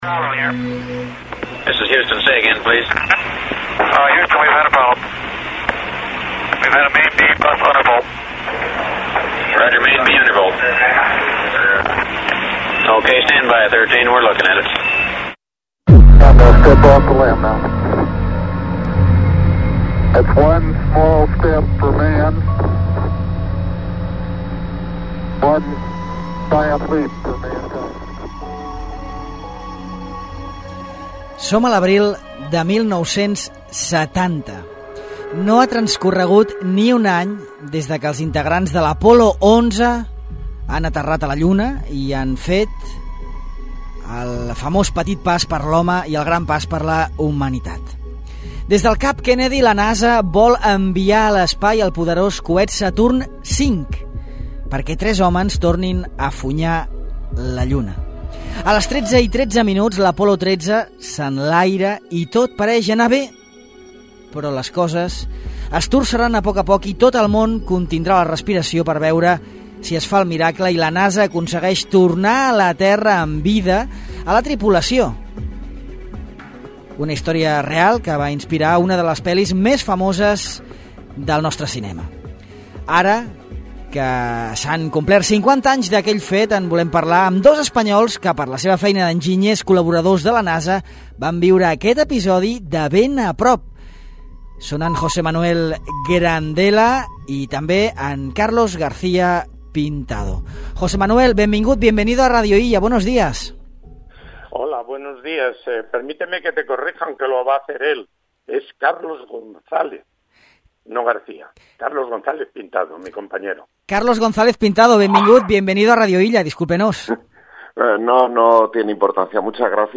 Revivim el rescat de l'Apol·lo 13, amb dos enginyers de la missió